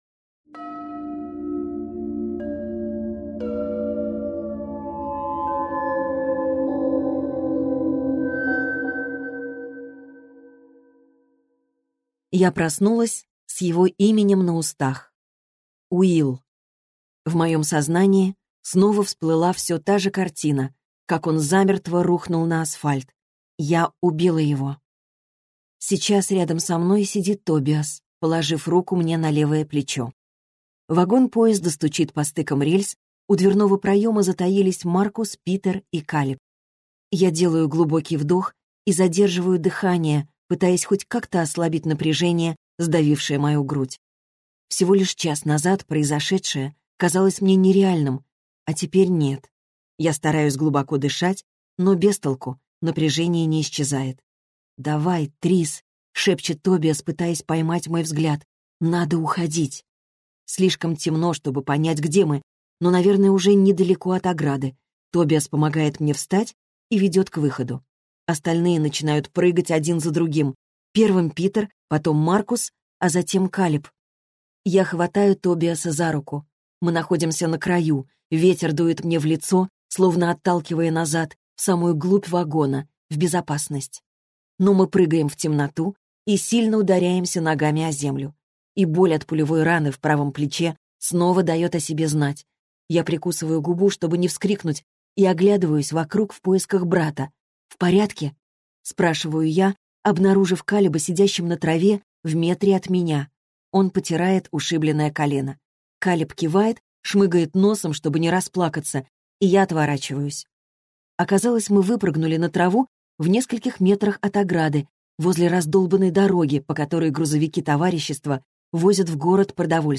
Аудиокнига Инсургент - купить, скачать и слушать онлайн | КнигоПоиск